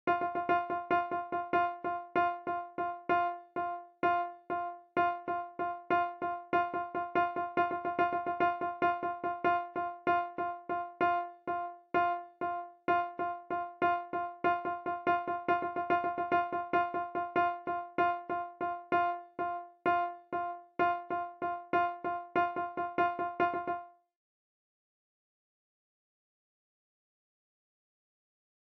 Beat gets faster.